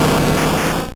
Cri de Tortank dans Pokémon Or et Argent.